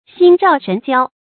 心照神交 注音： ㄒㄧㄣ ㄓㄠˋ ㄕㄣˊ ㄐㄧㄠ 讀音讀法： 意思解釋： 心照：心里了解；神交：忘形之交。